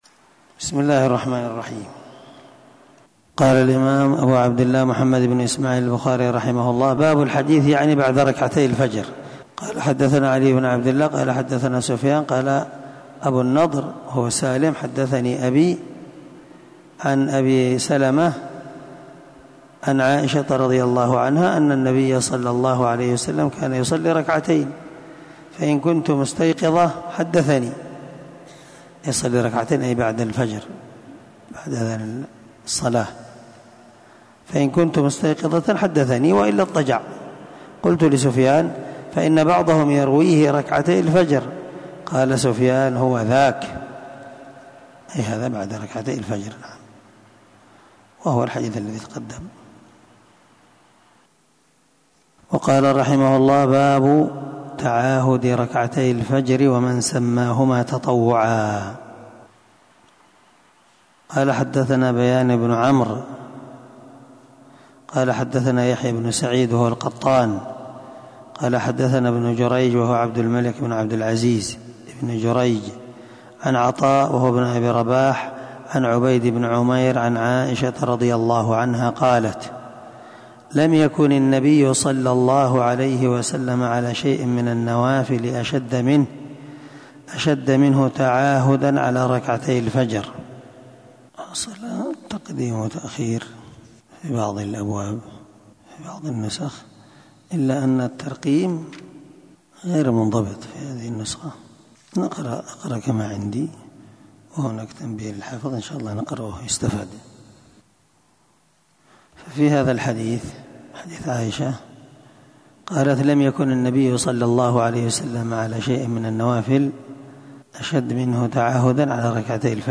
سلسلة_الدروس_العلمية
دار الحديث- المَحاوِلة- الصبيحة